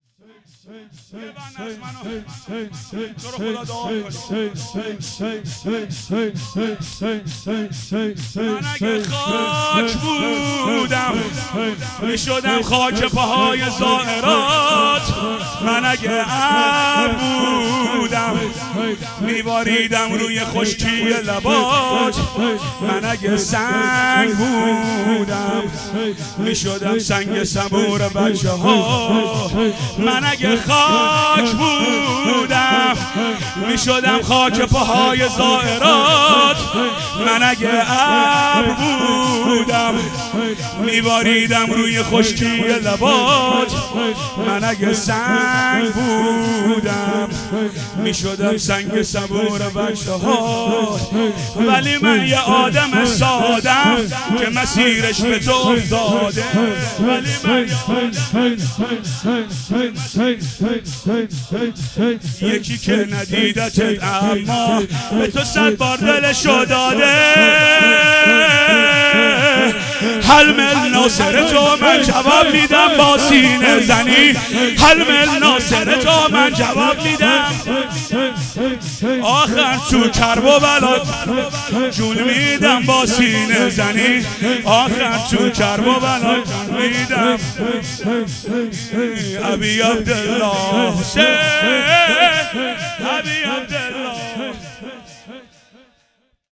هیئت حسن جان(ع) اهواز - شور | من اگه خاک بودم
دهه اول محرم الحرام ۱۴۴۴